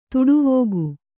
♪ tuḍuvōgu